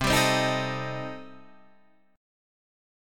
Cm6 Chord
Listen to Cm6 strummed